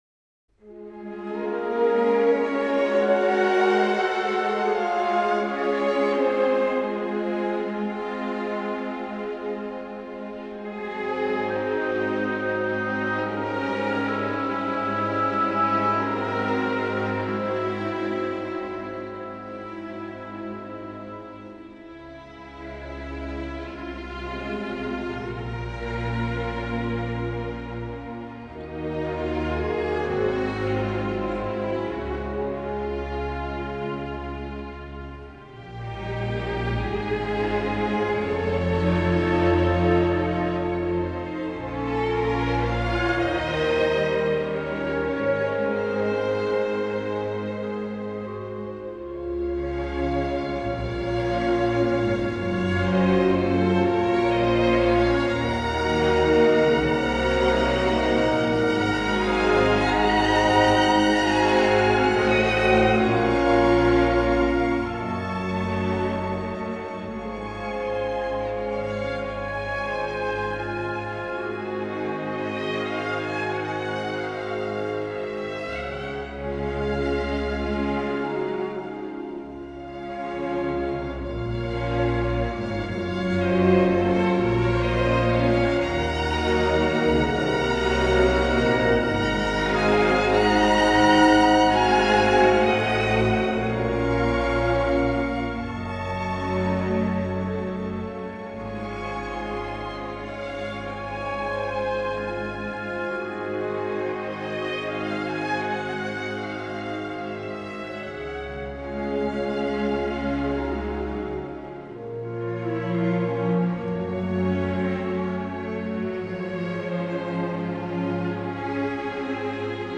Предлагаю немного лирической музыки